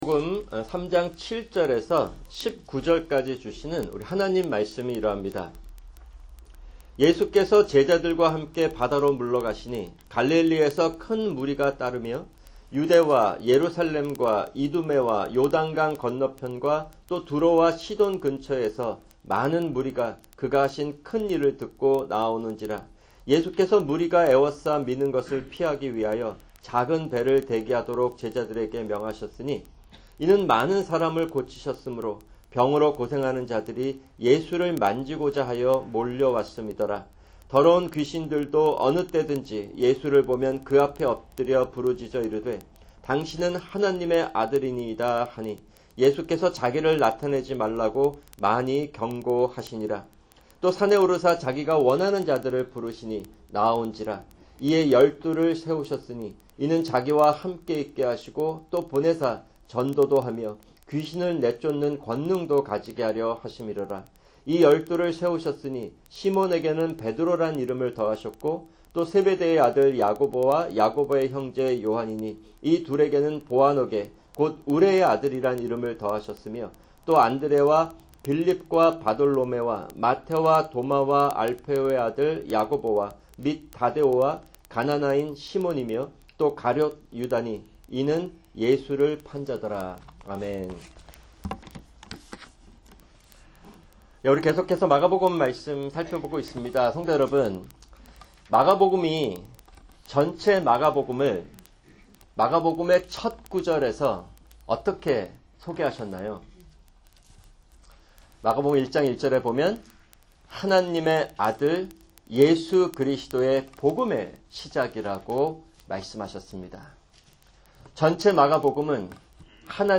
[주일 성경공부] 교회 멤버쉽 – 칭의(5)